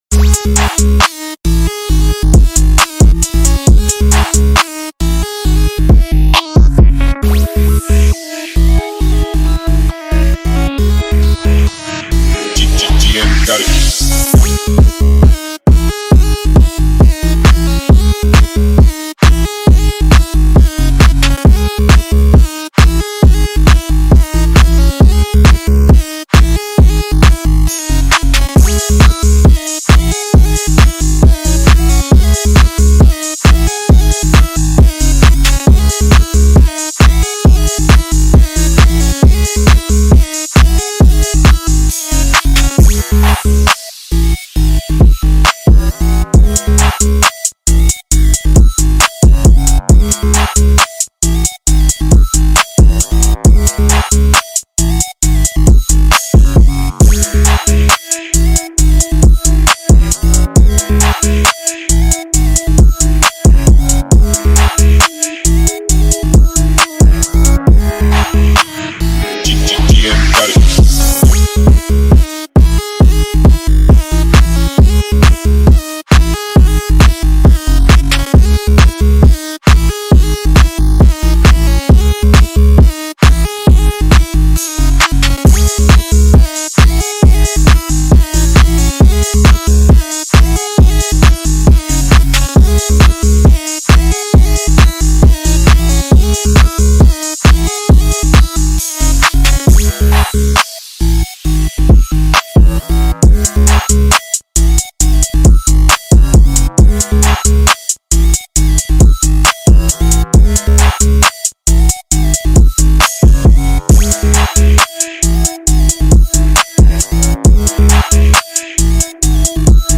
Dance/Club Instrumental